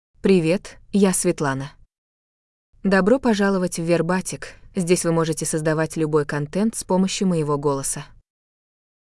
Svetlana — Female Russian AI voice
Svetlana is a female AI voice for Russian (Russia).
Voice sample
Female
Svetlana delivers clear pronunciation with authentic Russia Russian intonation, making your content sound professionally produced.